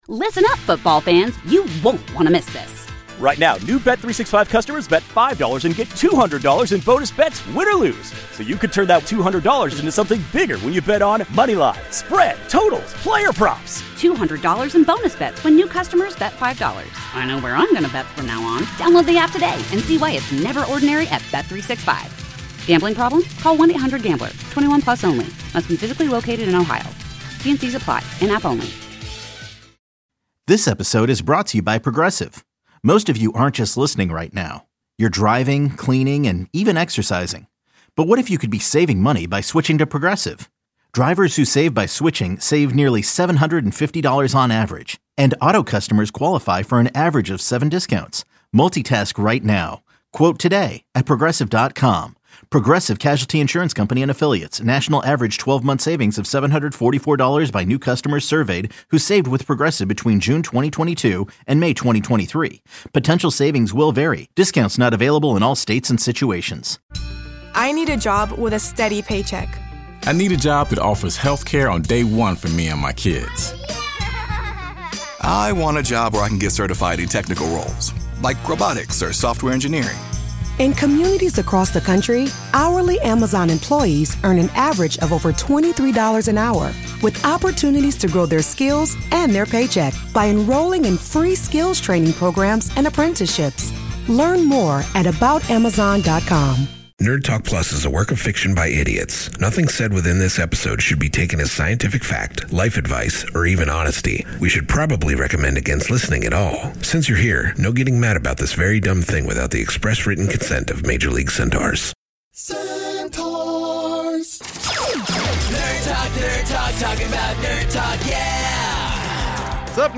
Nerd Talk+: Nerd Culture, Space, Robots, Comedy and Beyond…